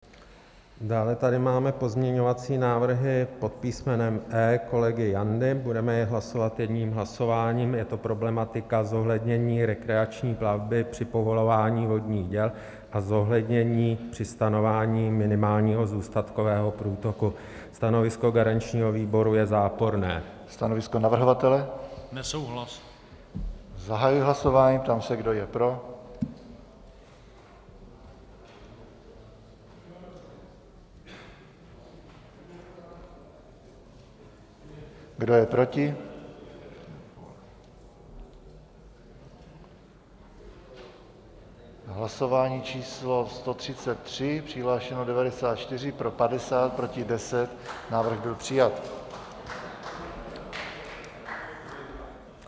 „Kdo je pro, kdo je proti?“ ptal se předseda PSP Radek Vondráček a pokračoval: „Hlasování číslo 133. Přihlášeno 94, pro 50, proti 10. Návrh byl přijat.“
Ve sněmovně se ozval potlesk několika poslanců.
Nastává 20 s pauza na hlasování.
Zvukový záznam z jednání je